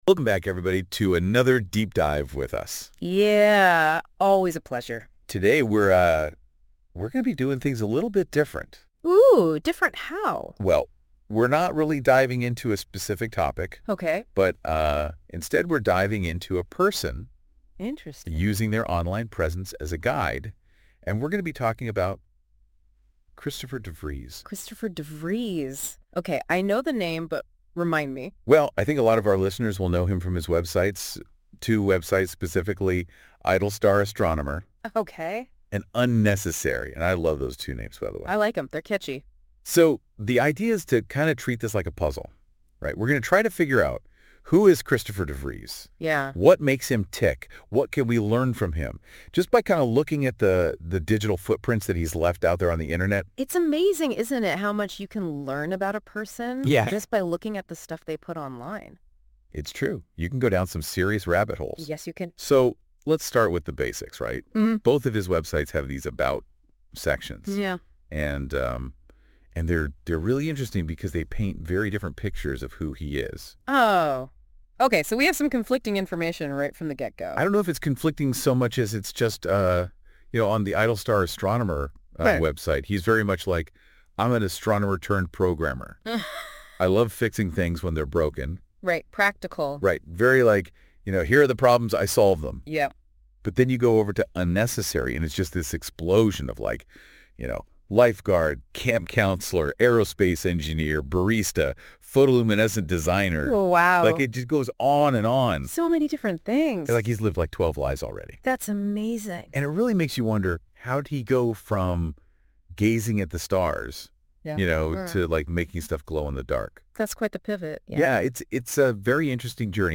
It does however sometimes produce amusing result, for example this AI generated podcast about me.